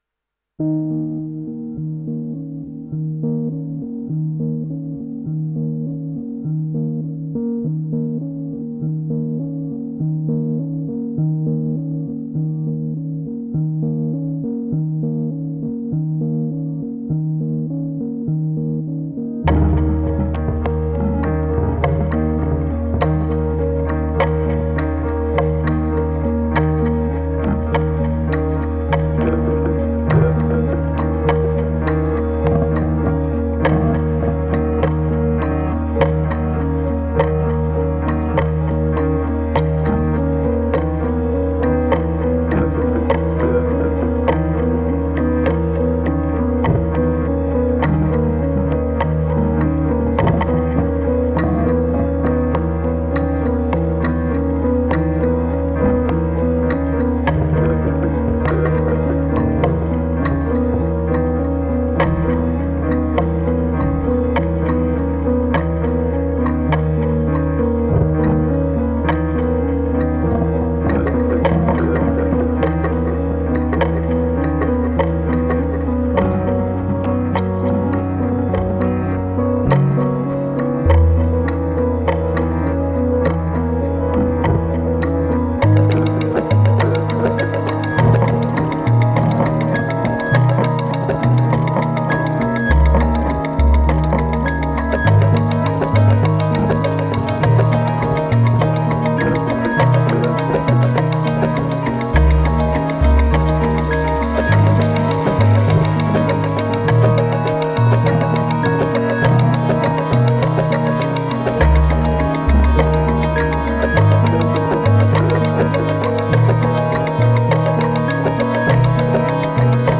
آهنگ الکترونیک بیکلام
electronic music